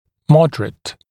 [‘mɔdərət][‘модэрэт]умеренный, средний